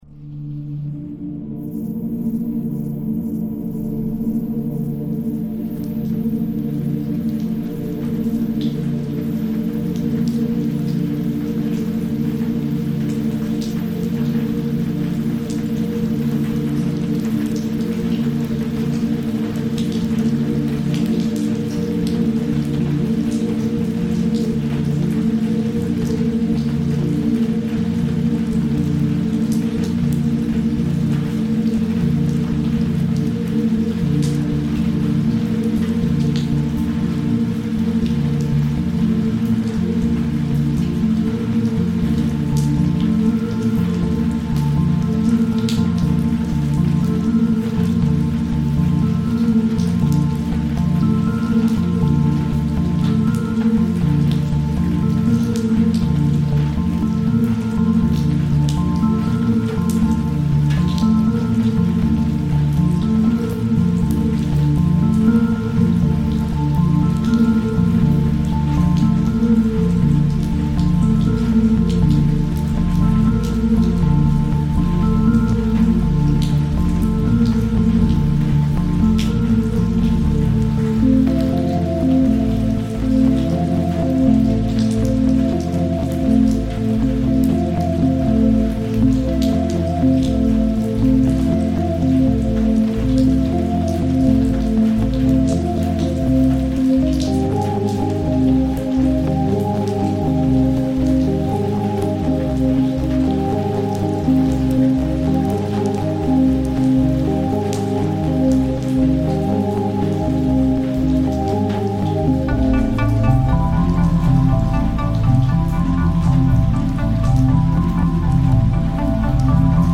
Night rain in Beijing reimagined